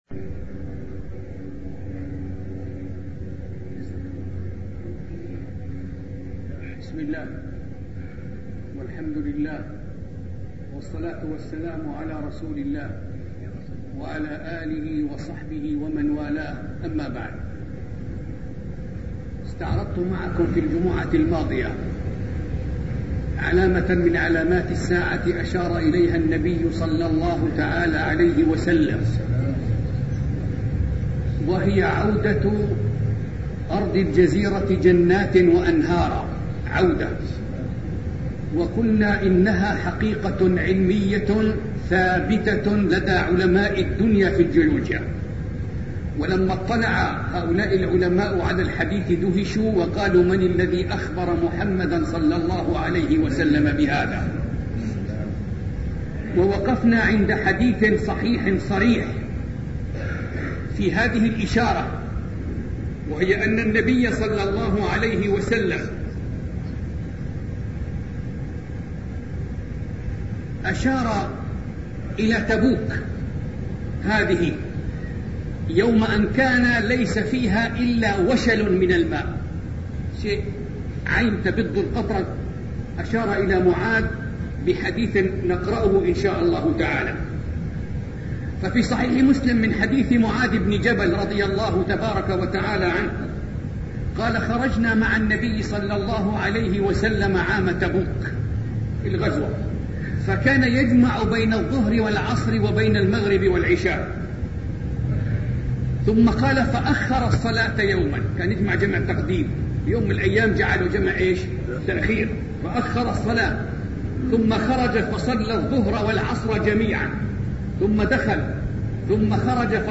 سلسلة محاضرات أشراط الساعة الوسطئ